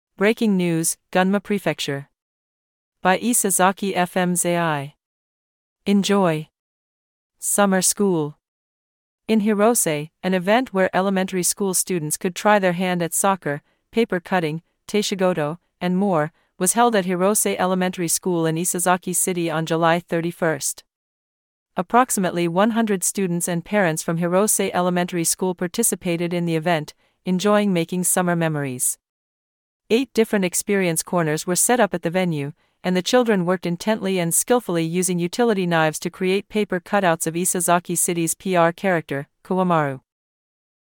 Breaking news "Gunma Prefecture".By Isesaki FM's AI."Enjoy!
Audio Channels: 1 (mono)